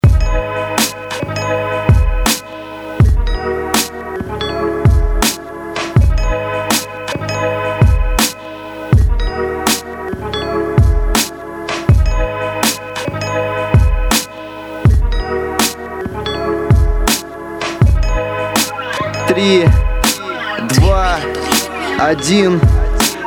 • Качество: 320, Stereo
ритмичные
русский рэп
спокойные